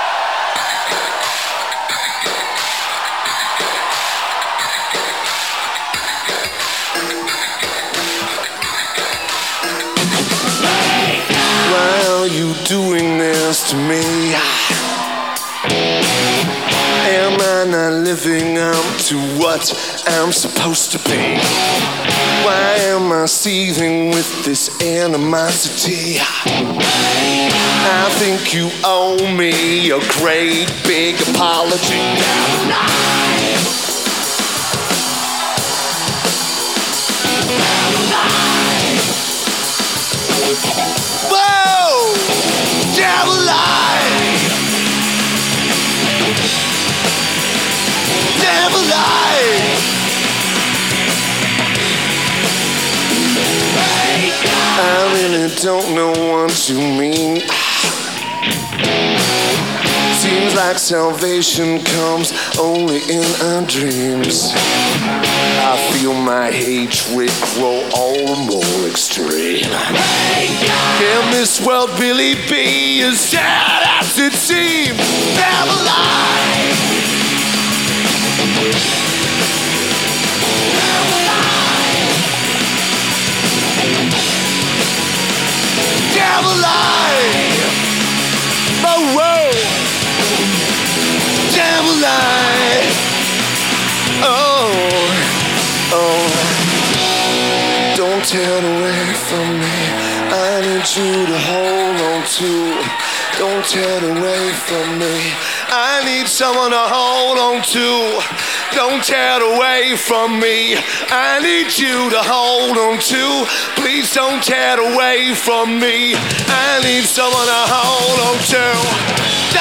Studio Coast
Lineage: Audio - PRO, IEM Feed